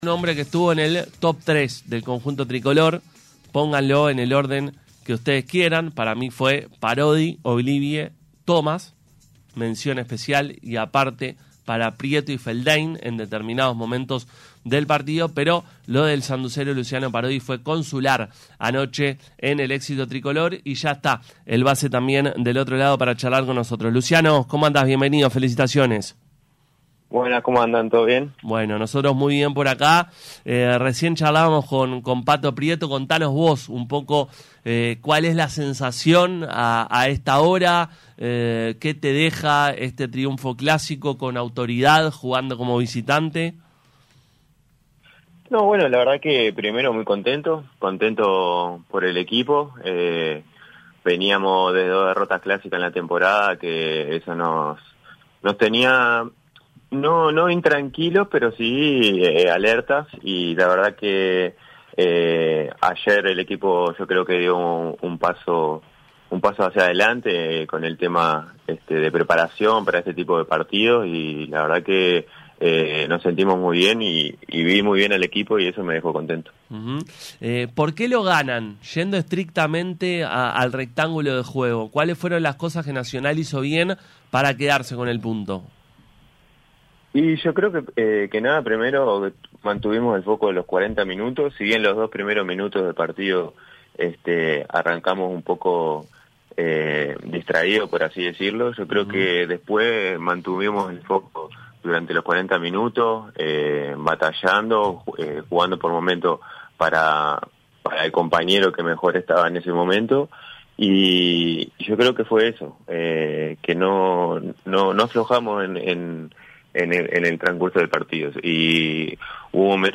Luciano Parodi jugador de Nacional habló con Pica La Naranja luego del triunfo clásico ante Peñarol.